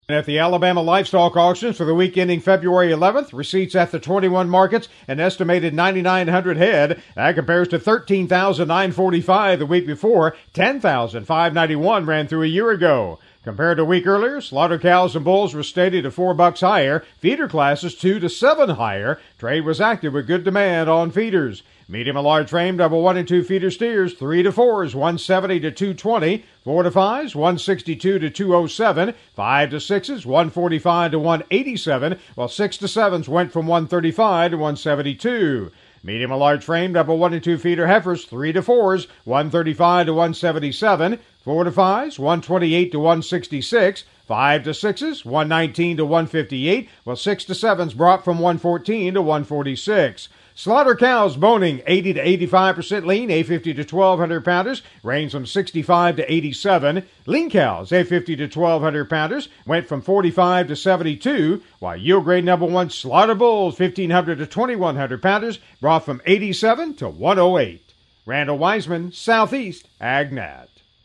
AL Weekly Livestock Market Report